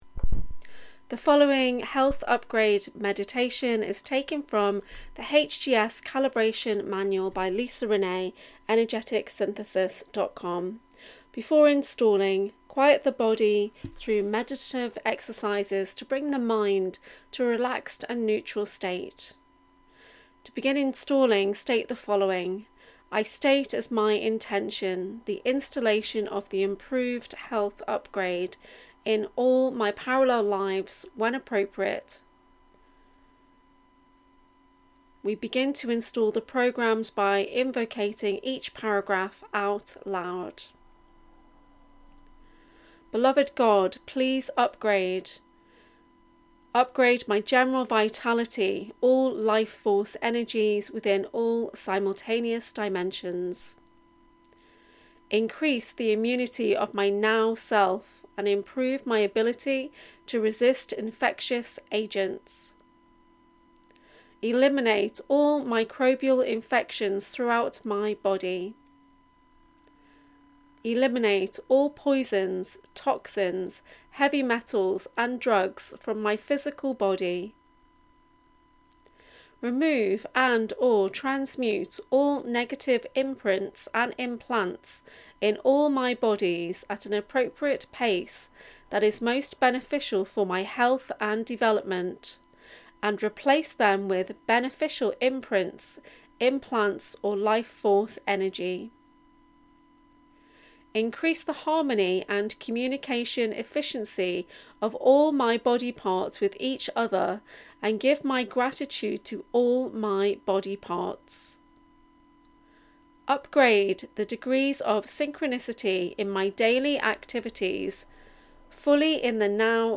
HGS Health Upgrade Meditation (mp3)